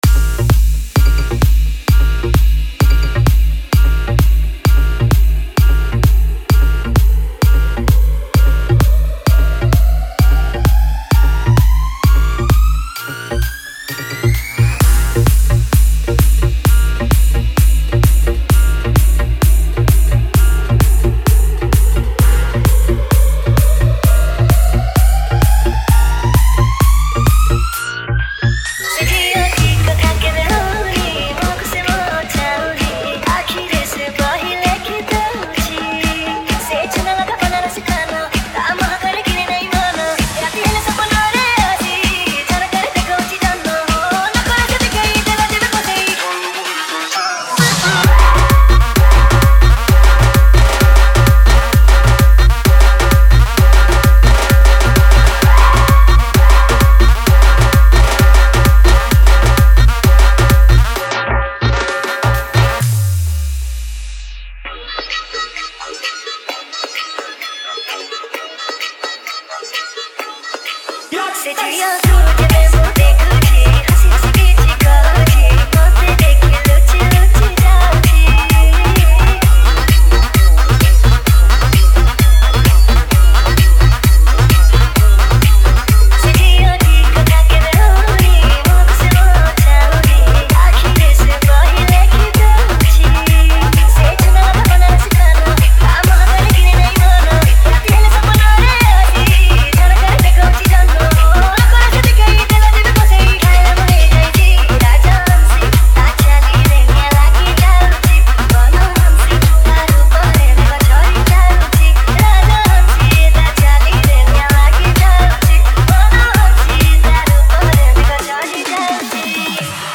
TRANCE MIX